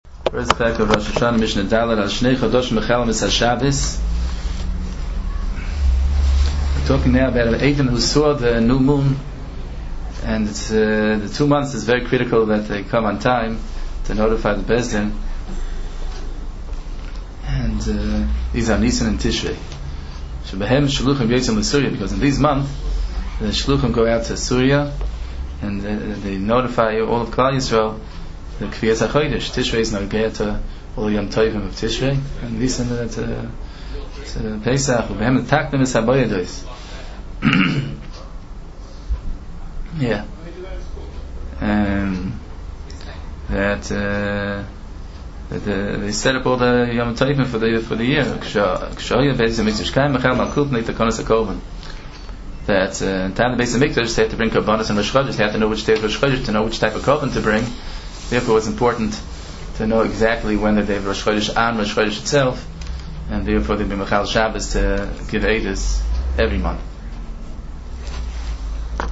Hear the Mishnah and its Halachos